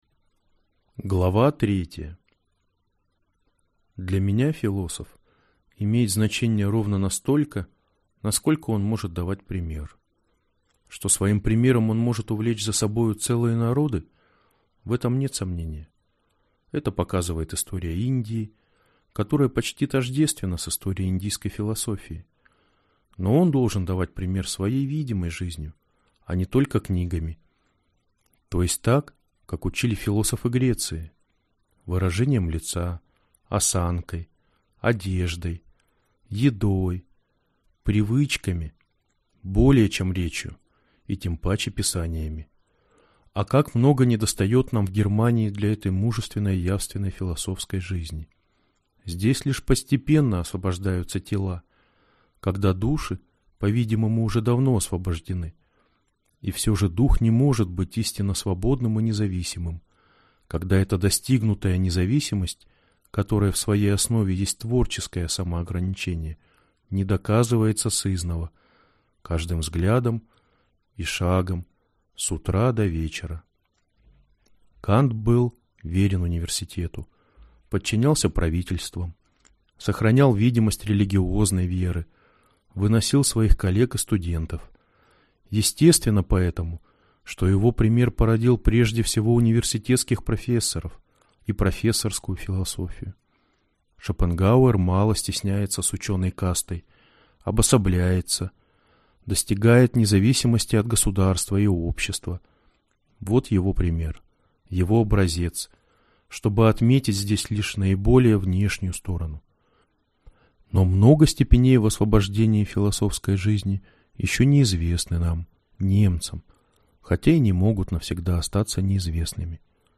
Аудиокнига Несвоевременные размышления: Шопенгауэр как воспитатель | Библиотека аудиокниг